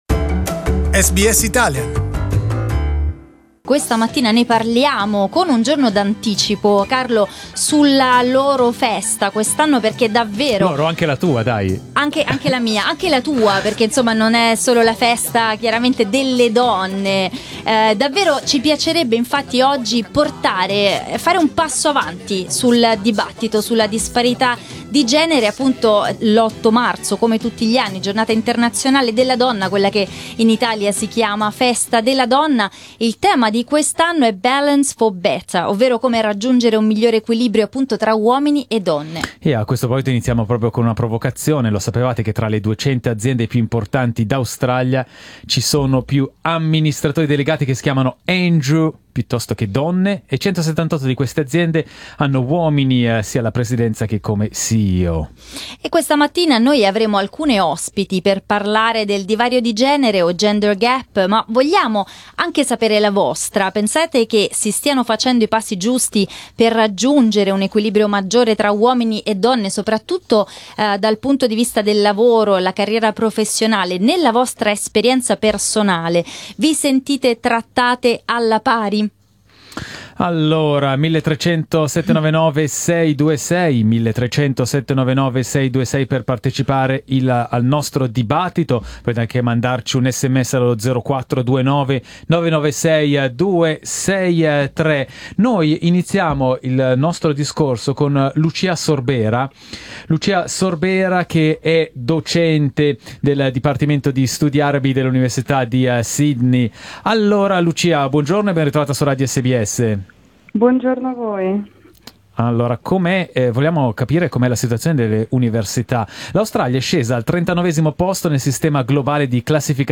Ahead of this year's International Women's Day we spoke to two prominent women from the Italian community in Australia about gender balance.